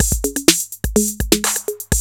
TR-808 LOOP1 2.wav